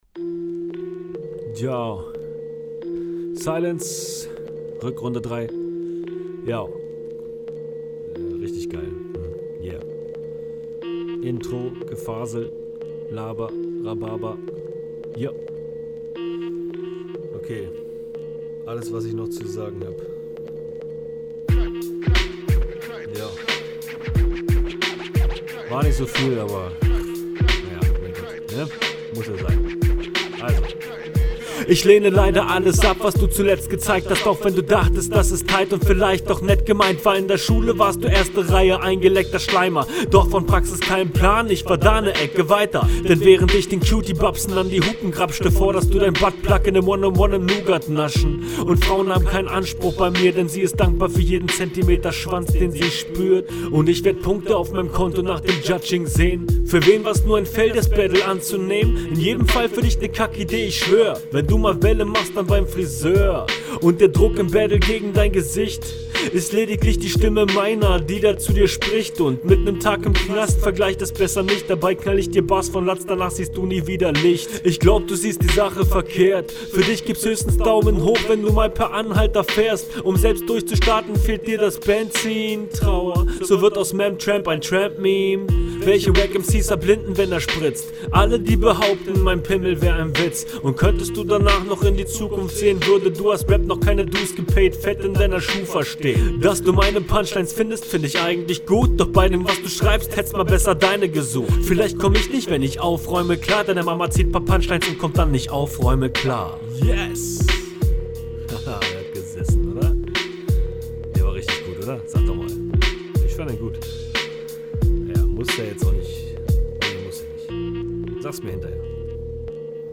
Cringe intro laber Rhabarber, du rappst das wirklich komplett on point, kontermäßig alles rausgeholt, flow …